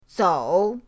zou3.mp3